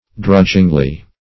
drudgingly - definition of drudgingly - synonyms, pronunciation, spelling from Free Dictionary Search Result for " drudgingly" : The Collaborative International Dictionary of English v.0.48: Drudgingly \Drudg"ing*ly\, adv.
drudgingly.mp3